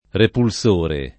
[ repul S1 re ]